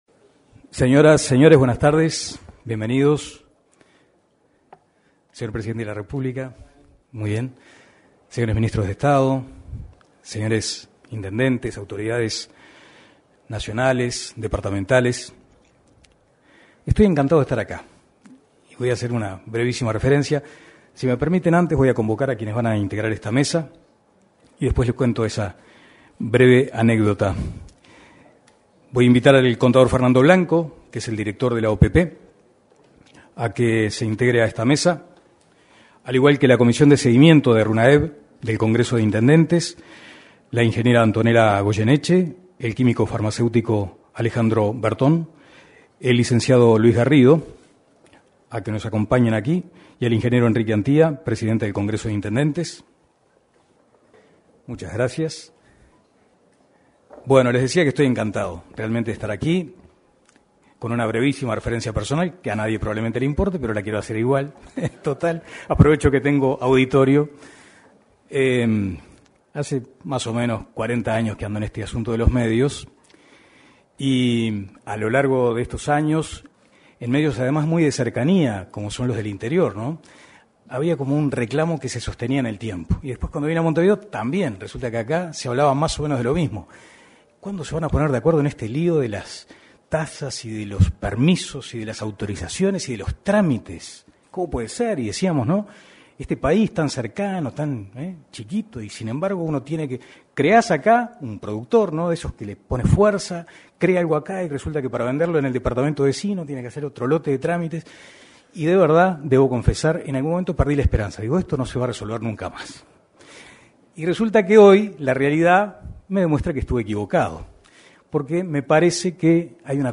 Lanzamiento del Registro Único Nacional de Alimentos, Empresas y Vehículos 05/11/2024 Compartir Facebook X Copiar enlace WhatsApp LinkedIn En el salón de actos de la Torre Ejecutiva, se desarrolló el lanzamiento del Registro Único Nacional de Alimentos, Empresas y Vehículos (Runaev).